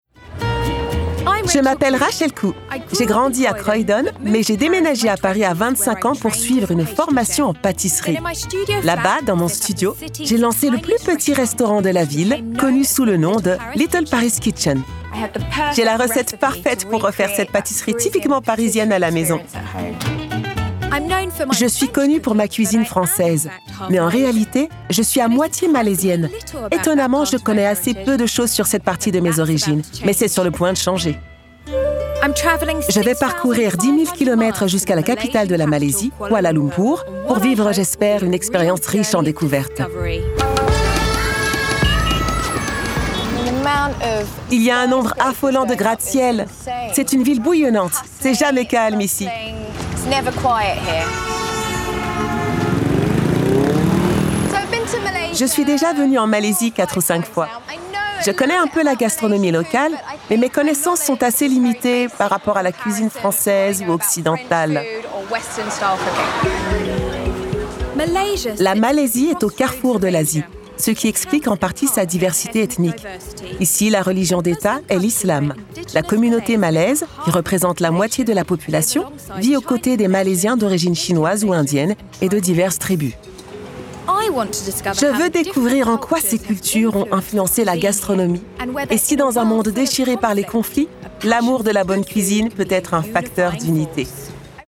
♀ fr
Voice Over